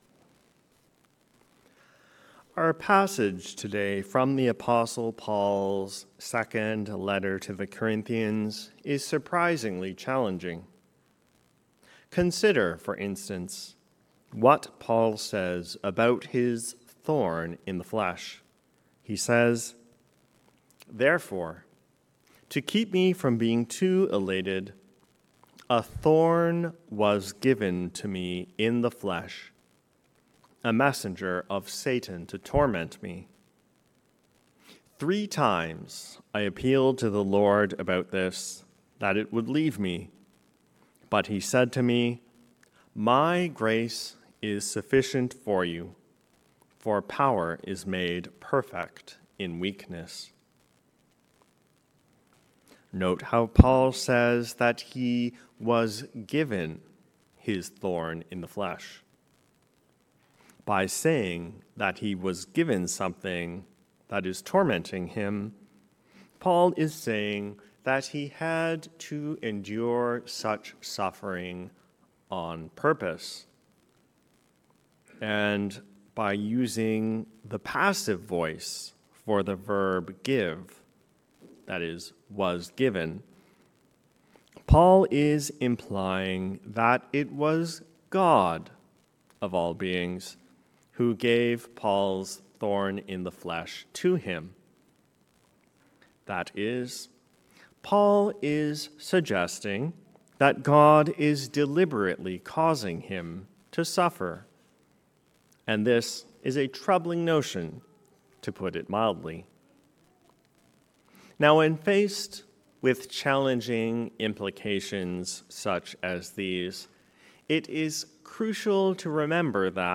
A Sermon for the Seventh Sunday After Pentecost
Sermon-7-July-2024.mp3